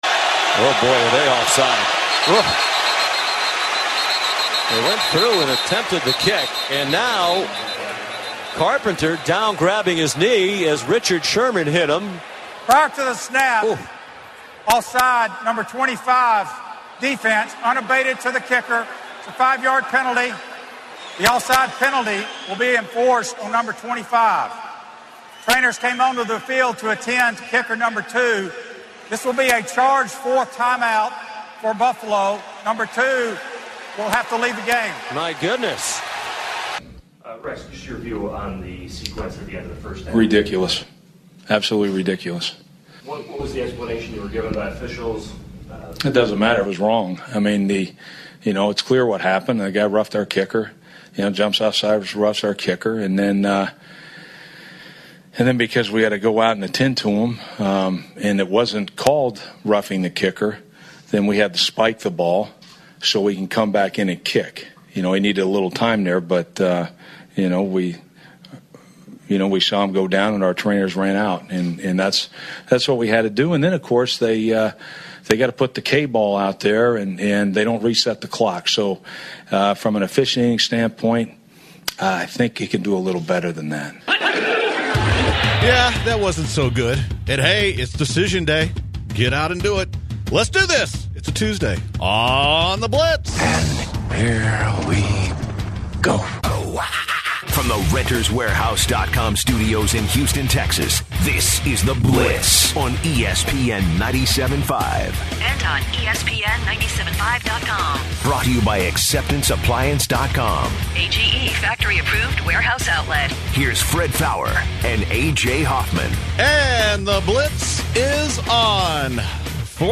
They bring employees and other hosts on air to talk about who they voted for and why.